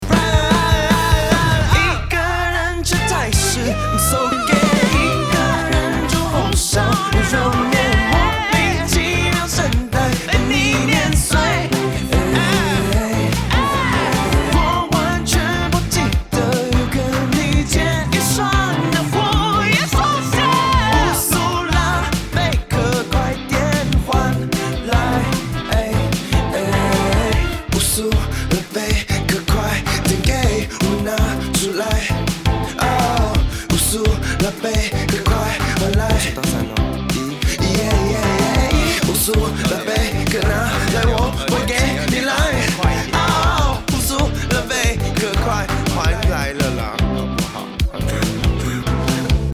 Disco, Dance-pop, Funk
authentic taiwanese disco band